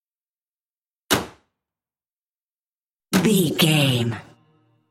Car hood close
Sound Effects